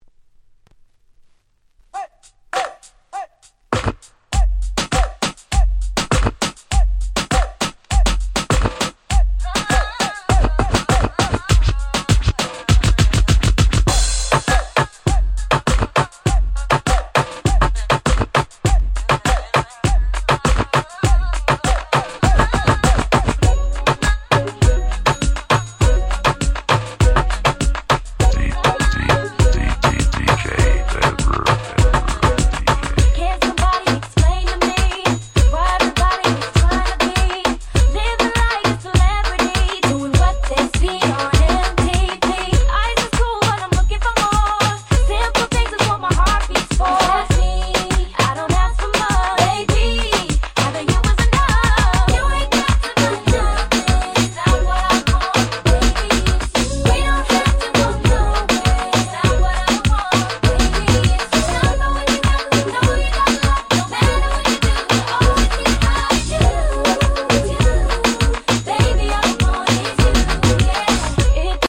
00's R&B